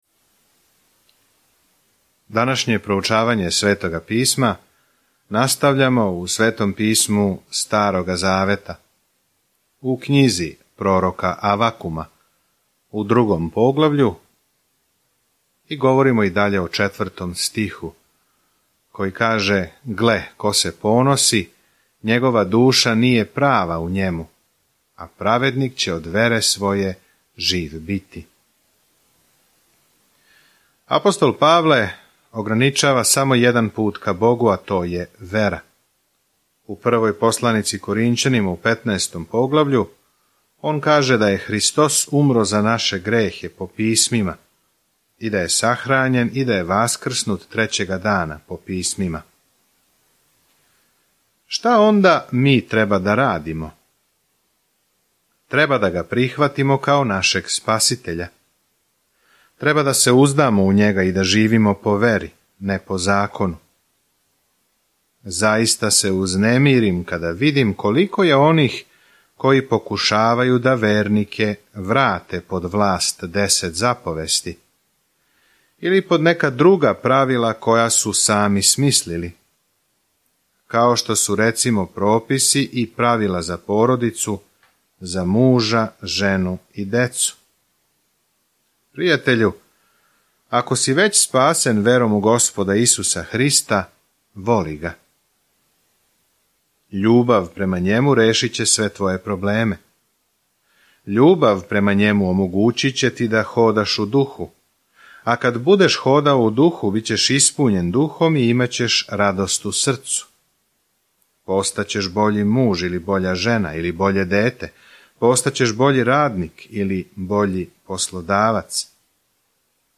Sveto Pismo Knjiga proroka Avakuma 2:20 Knjiga proroka Avakuma 3:1-5 Dan 5 Započni ovaj plan Dan 7 O ovom planu Авакум пита велико „зашто, Боже?“ На почетку низа питања и одговора са Богом о томе како он ради у опаком свету. Свакодневно путујте кроз Авакума док слушате аудио студију и читате одабране стихове из Божје речи.